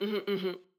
VO_ALL_Interjection_15.ogg